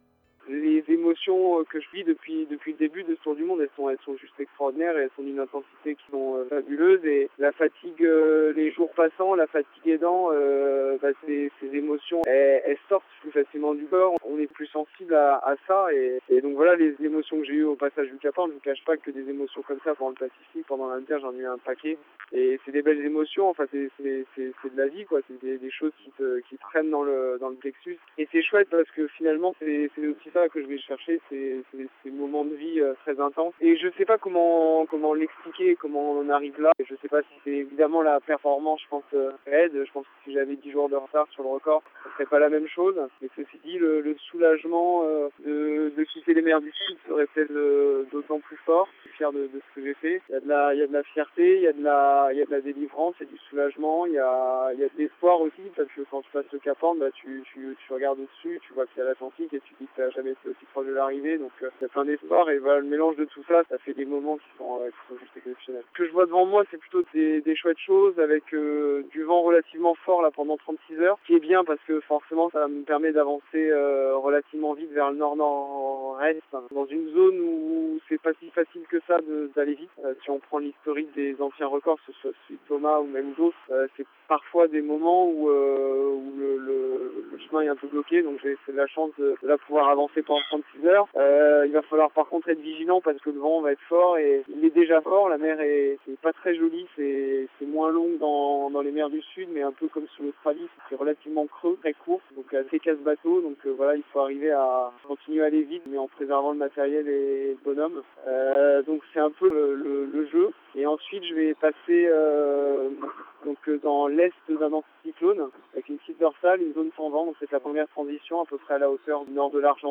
Nous avons joint ce lundi, François Gabart au téléphone le lendemain de son passage au Cap Horn. Il avait de la peine à cacher ses émotions, mélange de fatigue accumulée, peine à réaliser le chemin déjà parcouru jusqu’au Cap Horn mais surtout la certitude de vivre des moments intenses.
Son émotion était palpable au passage du Cap Horn.